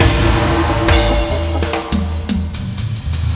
FILLBIG 01-L.wav